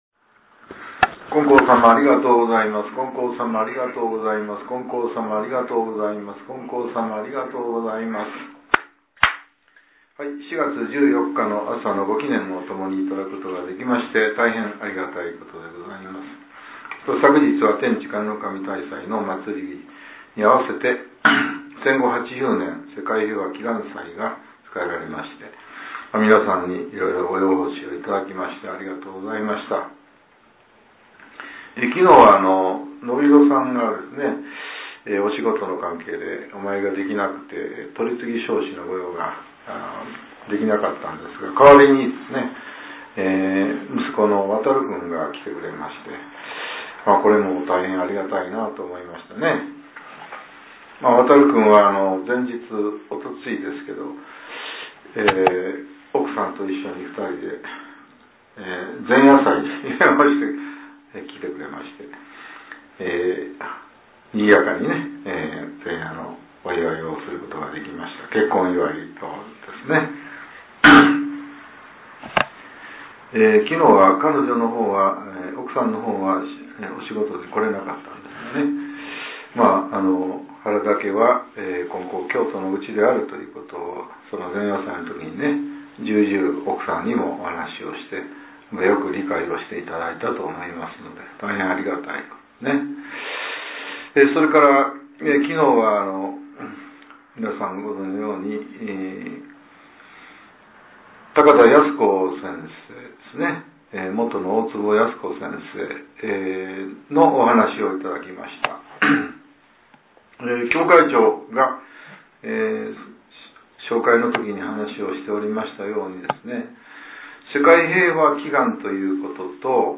令和７年４月１４日（朝）のお話が、音声ブログとして更新されています。